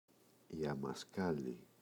αμασκάλη, η [amaꞋskali]